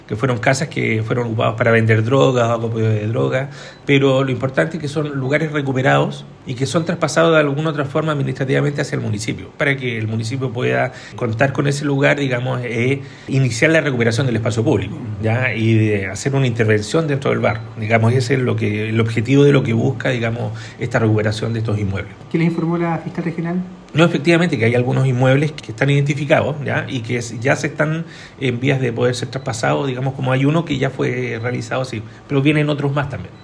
El coordinador regional de Seguridad Pública, Cristian Winter, afirmó que la Fiscalía les confirmó que hay inmuebles identificados para su recuperación y que uno de ellos ya fue traspasado a la Municipalidad de Valdivia.